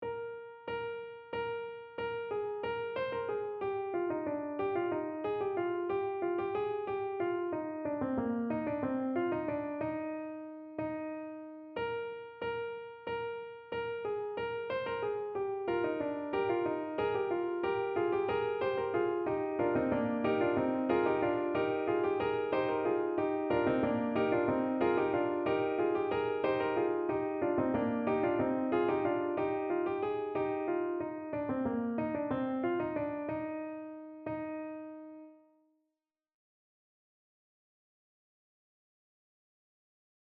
Transcribe the round we learned in class. A MIDI realization is below.